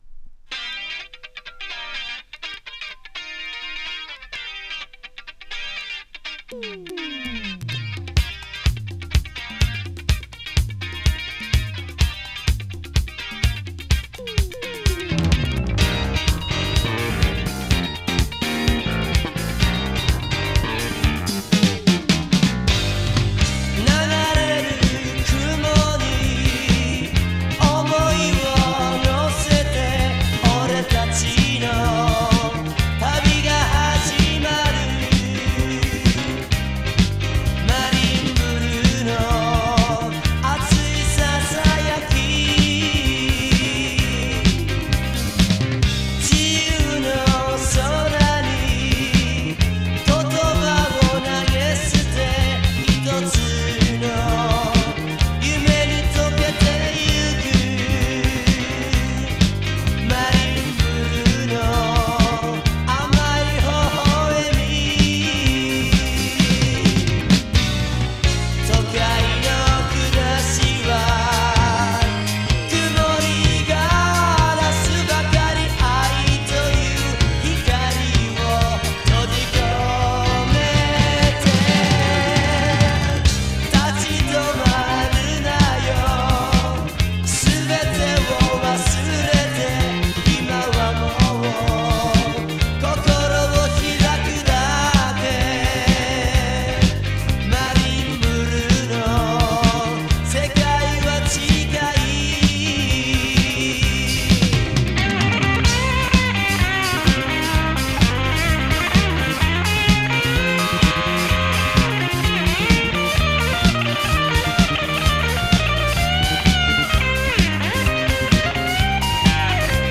ファンキーなブギーナンバーからメロウも揃った和モノファン見逃し厳禁作！！